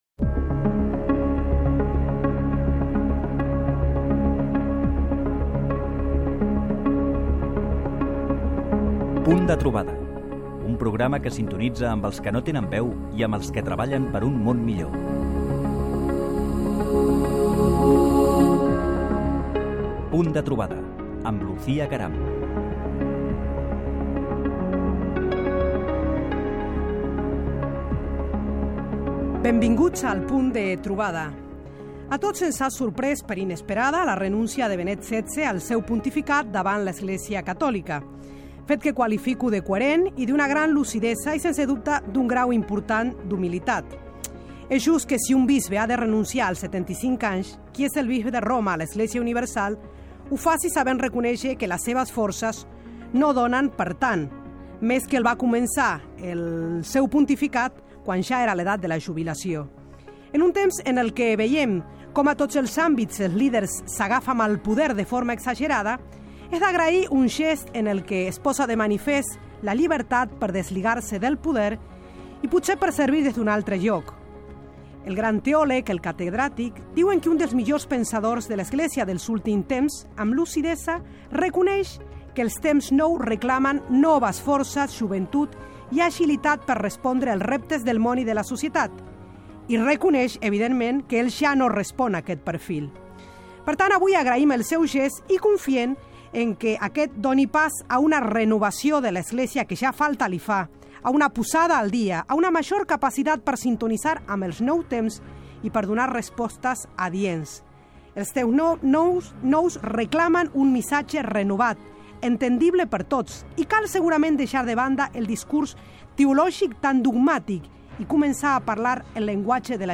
Careta del programa, comentari sobre la renúnicia del Sant Pare Benet XVI